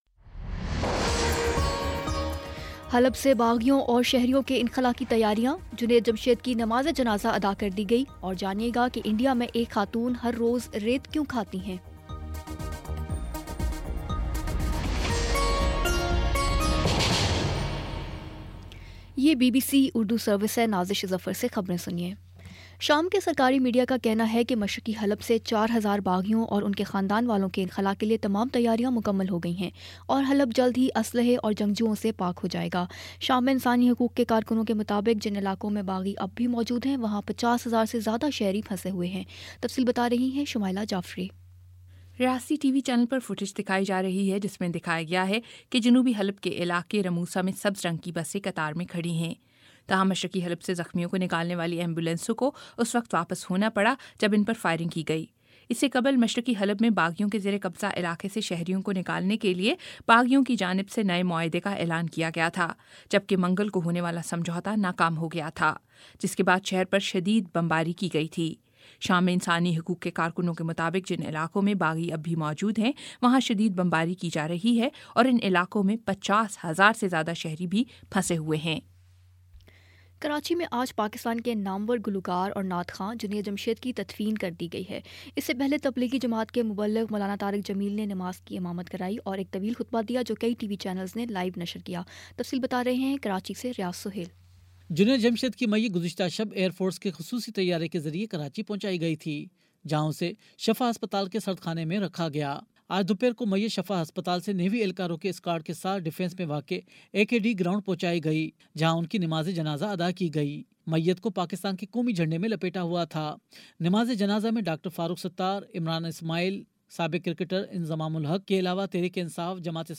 دسمبر 15 : شام چھ بجے کا نیوز بُلیٹن